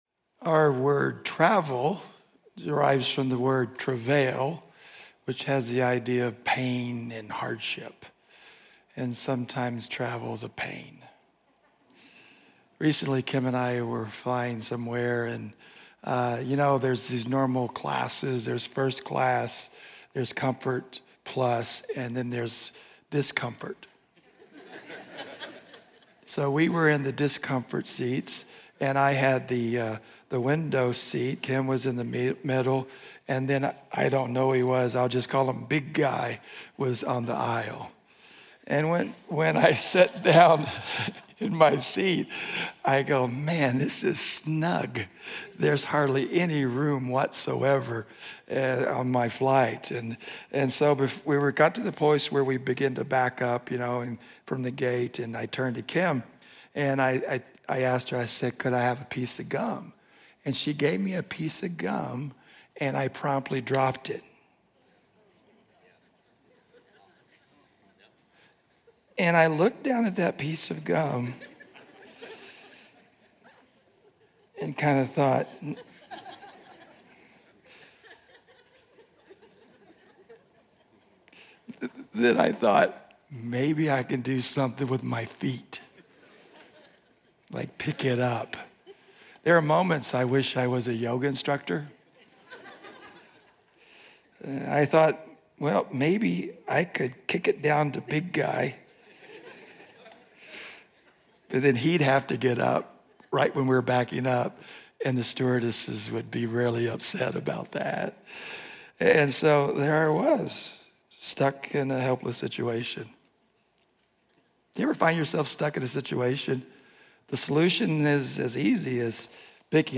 Journey Church Bozeman Sermons